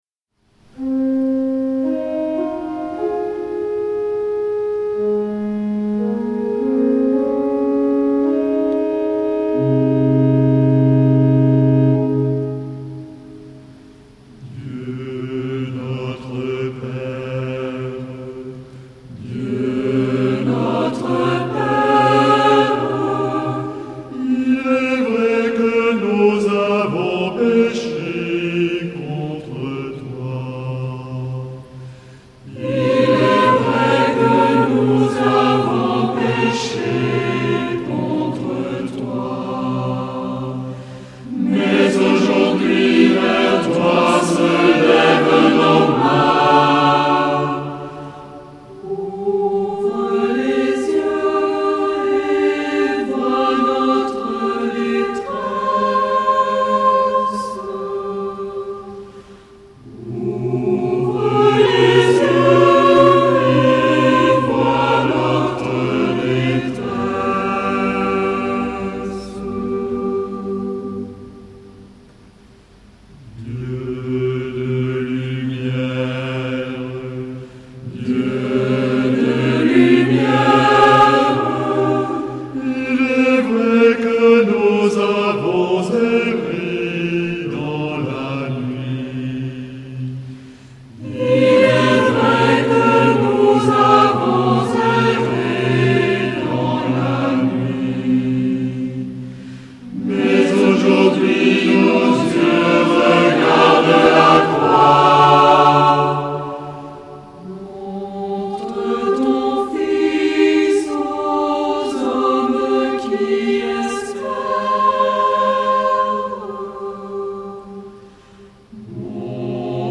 Genre-Style-Form: Prayer ; Invocation
Mood of the piece: pleading ; collected ; calm
Type of Choir: SAH  (3 mixed voices )
Tonality: C tonal center